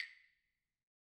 Claves1_Hit_v2_rr1_Sum.wav